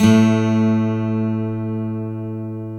Index of /90_sSampleCDs/Roland L-CD701/GTR_Steel String/GTR_18 String
GTR 12 STR0H.wav